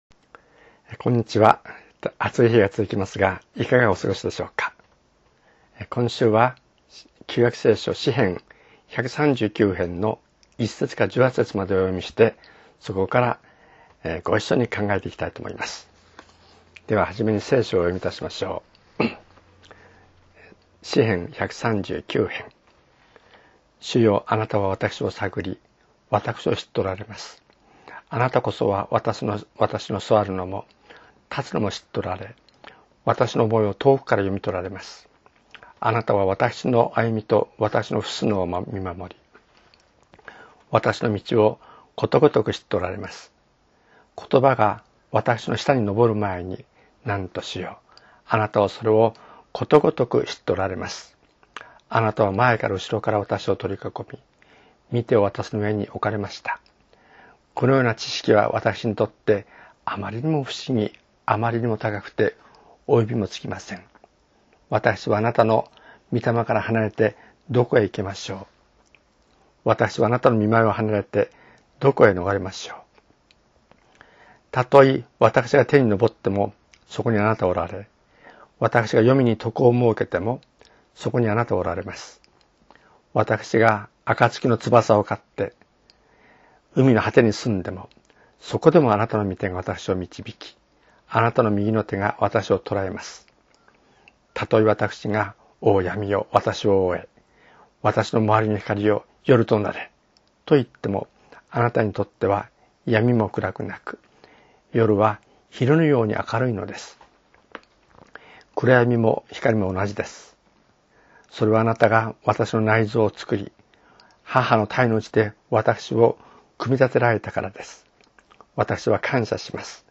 声のメッセージ